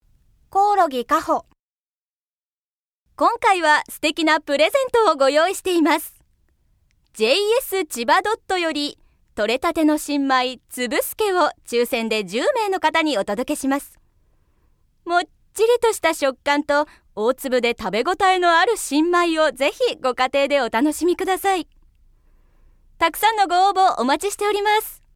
◆ラジオプレゼント告知風ナレーション◆